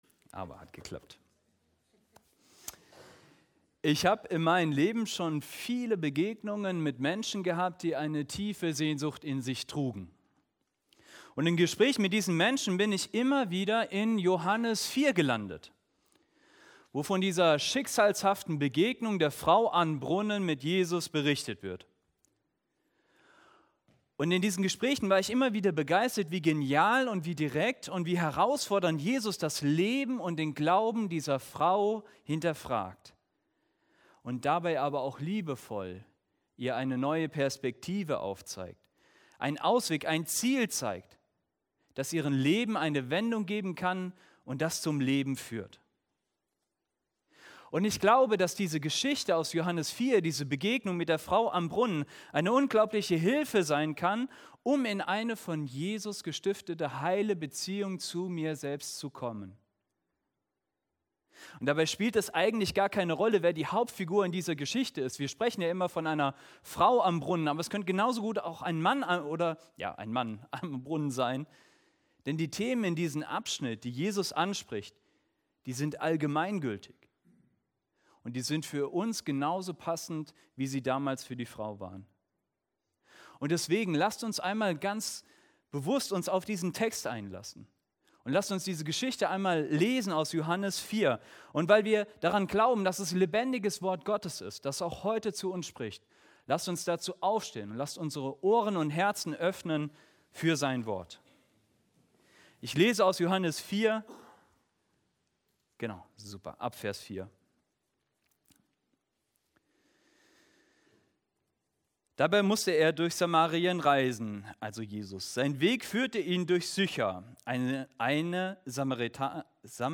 Predigt in der Evangelisch Freikirchlichen Gemeinde Herford